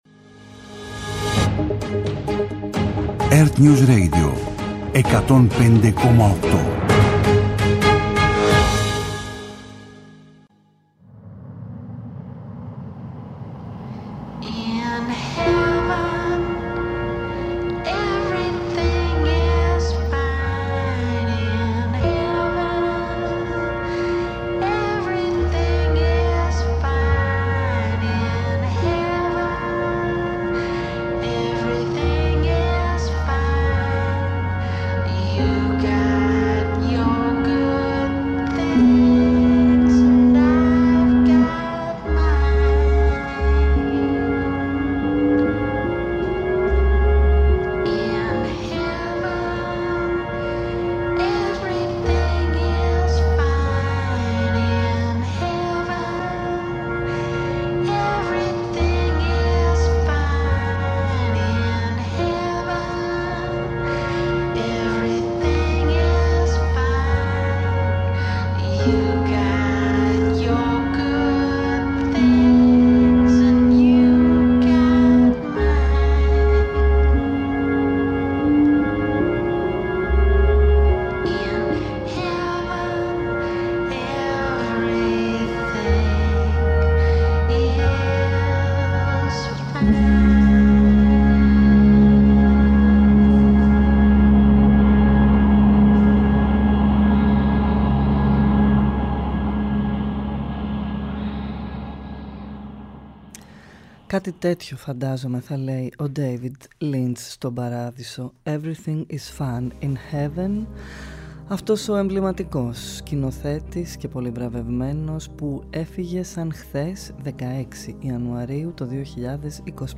ακούμε μουσικές και τραγούδια από ταινίες